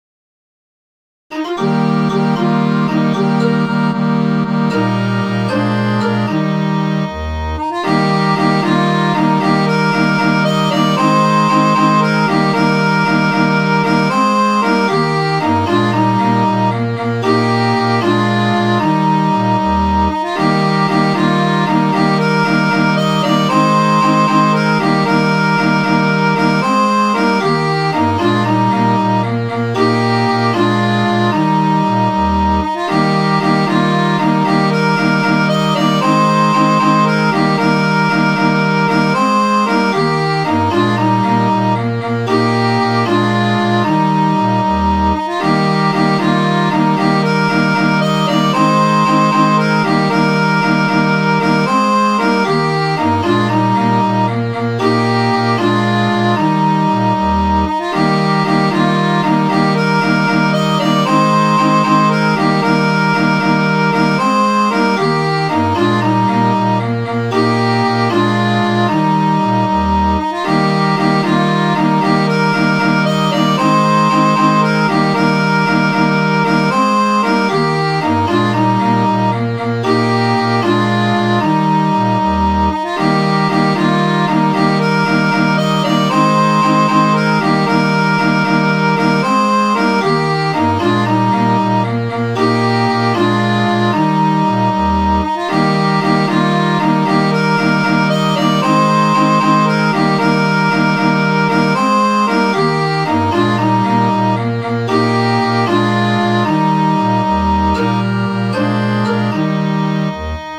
Midi File, Lyrics and Information to Hanging Johnny
This was one of the best and most popular halyard shanties. Additional verses, hanging anyone that came to mind, would be added to last as long as the job took.